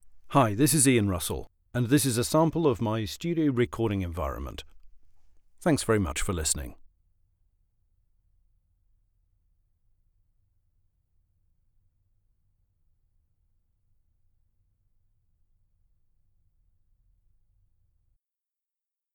Male
British English (Native)
Assured, Corporate, Deep, Engaging, Friendly, Gravitas, Natural, Smooth, Warm, Versatile
Luxury Commercial.mp3
Microphone: NeumannTLM103 Sennheiser 416